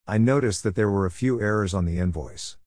・noticed /ˈnoʊtəst/の一つ目の/t/は前後が母音に囲まれているのでフラッピング
・noticed that /ˈnoʊtəst ðət/はnoticedの末尾の/st/部分で子音が連続していて次の語の頭も子音なので/t/が脱落
・thatはリダクションで弱形の/ðət/で発音し、次の単語が子音から始まるので語尾の/t/は閉鎖のみでリンキング
・were a /wər ə/とerrors on /ˈerərz ɔn/の二か所は子音＋母音のリンキング
少しスピードを落とした(90%のスピード)音声も下にあげておきますので必要に合わせて活用ください。